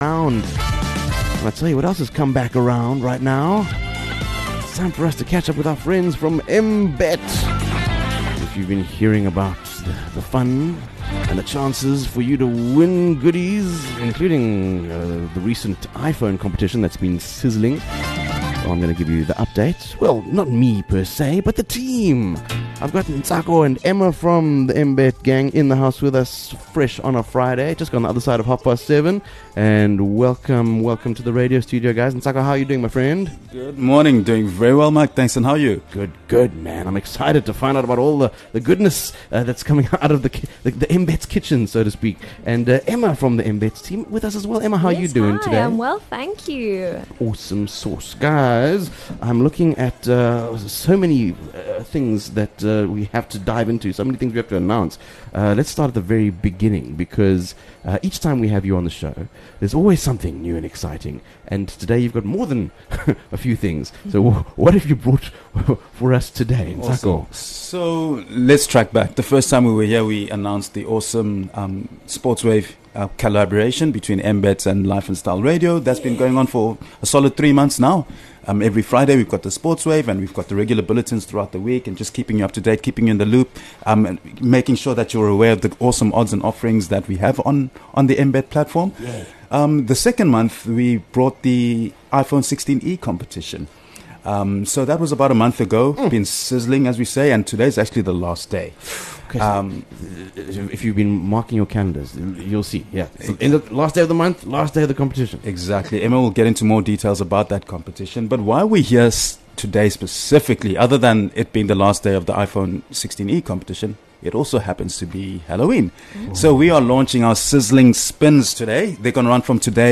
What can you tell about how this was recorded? These questions form a lively radio interview script highlighting Mbet’s latest promotions - including Halloween-themed offers, jackpot races, and an ongoing iPhone giveaway - aimed at engaging both new and existing users with exciting updates and rewards.